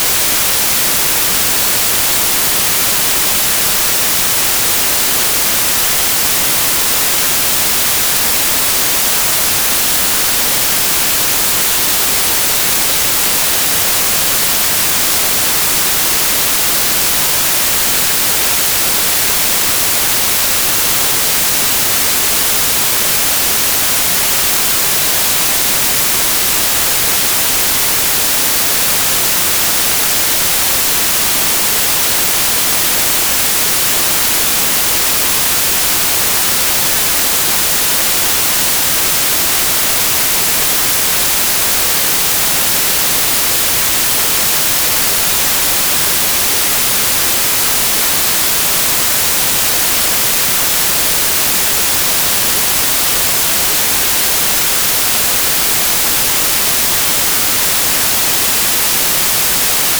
spk_cal_whitenoise.wav